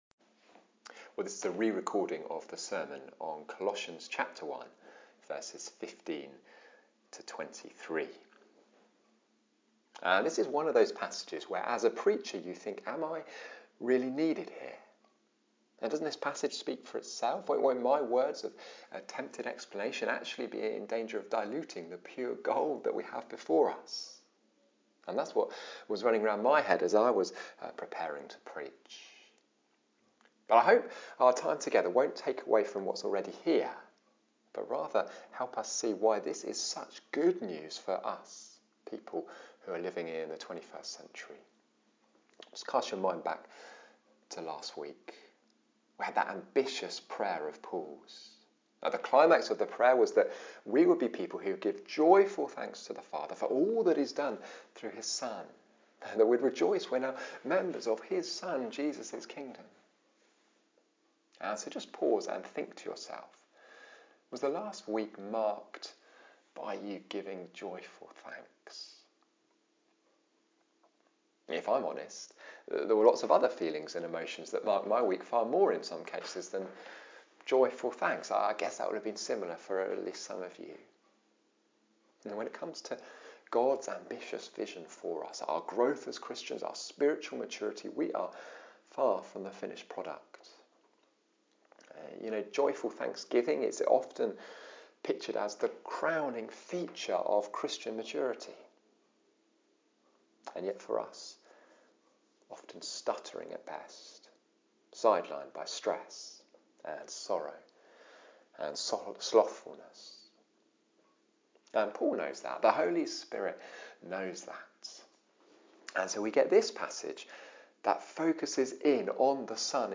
Col-1.15-23-Sermon.mp3